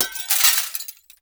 GLASS_Window_Break_02_mono.wav